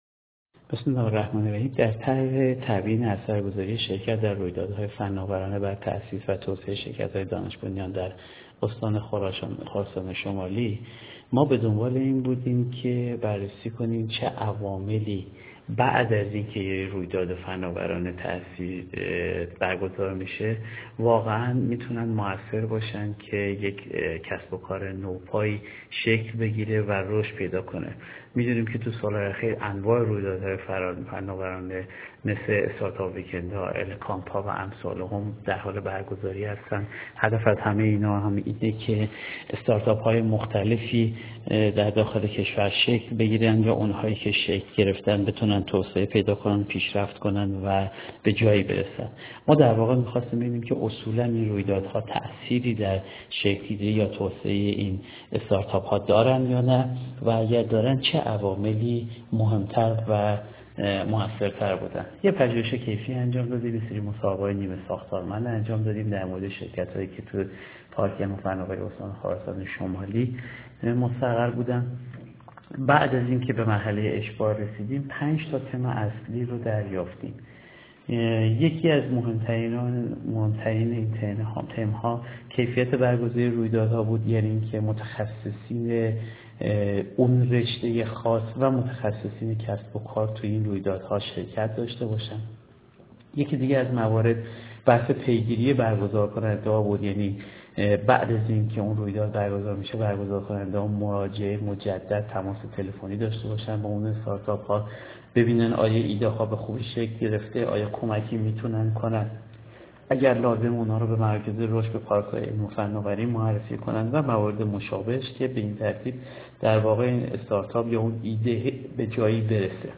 مصاحبه تبیین اثر گذاری شرکت در رویدادهای فناورانه بر تاسیس و توسعه شرکت های دانش بنیان در استان خراسان شمالی.aac